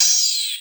Gamer World Open Hat 2.wav